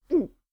gulp1.wav